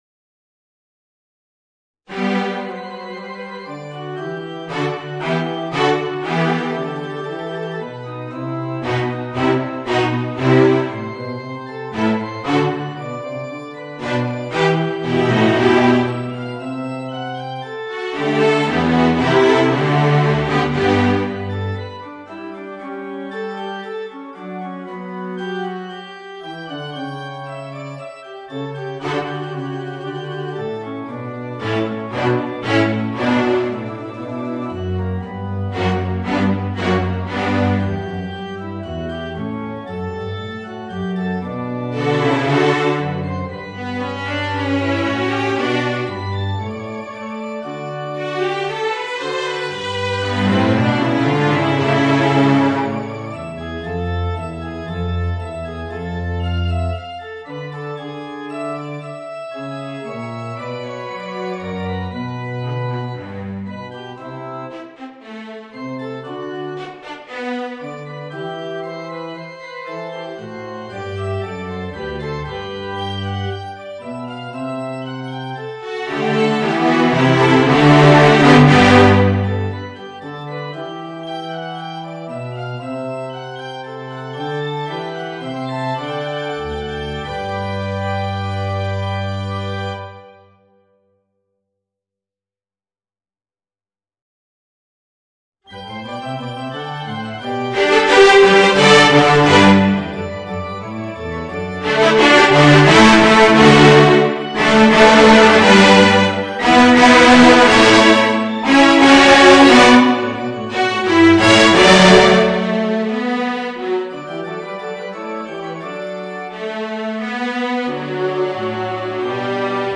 Voicing: 2 Violas, Violoncello and Organ